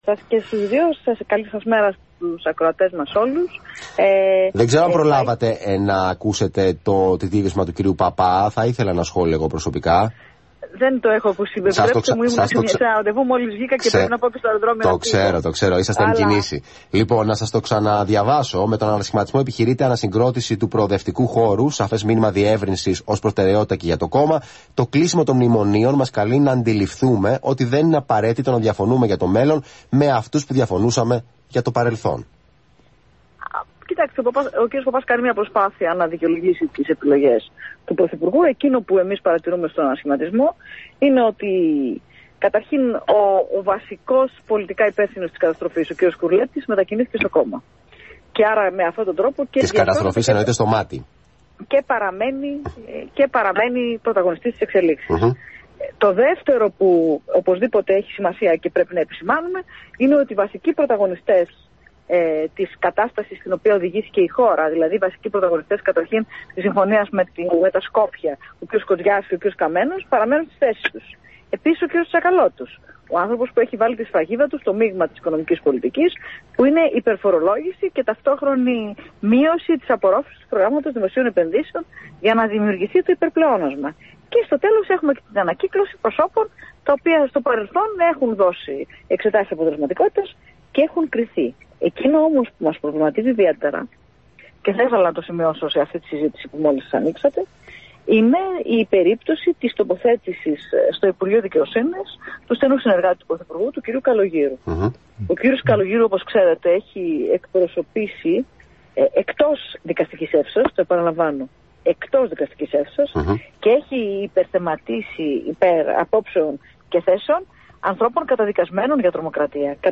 Μιλώντας στον Αθήνα 9.84 και στην εκπομπή “Αθήνα Σήμερα“